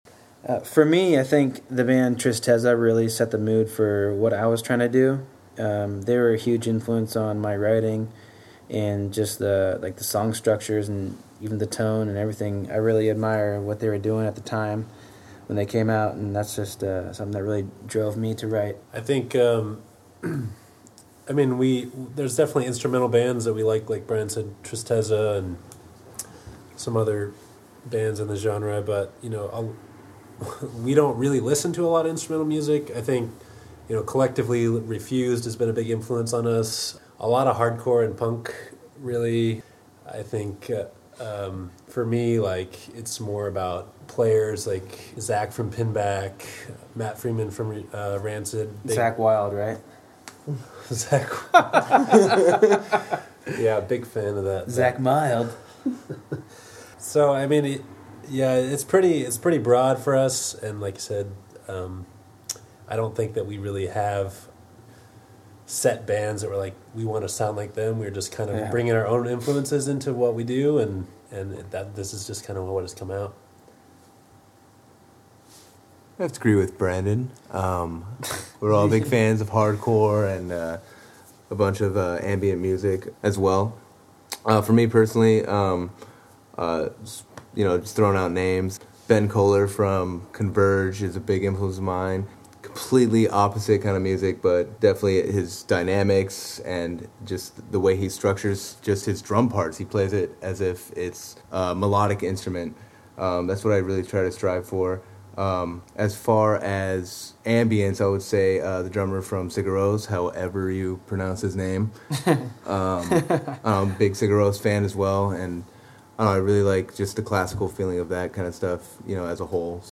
YOU MAY DIE IN THE DESERT INTERVIEW- October 2012